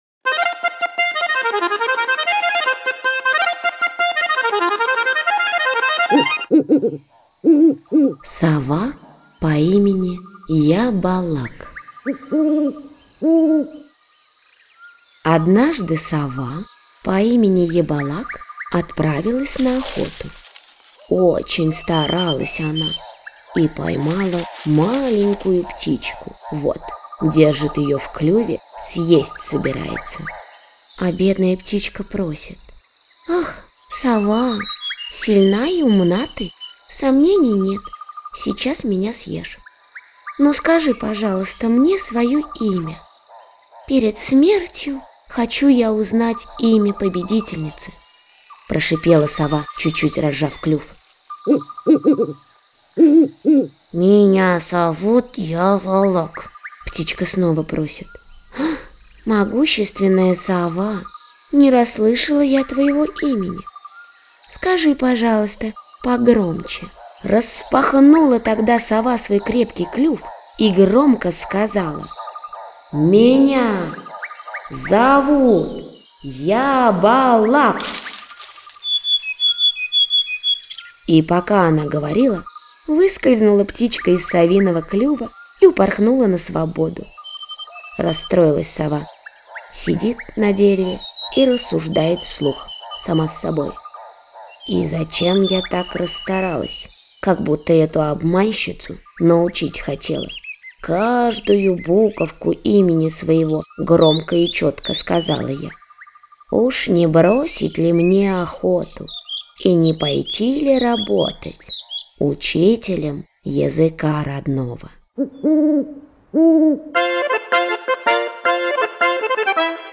Сова по имени Ябалак - татарская аудиосказка - слушать онлайн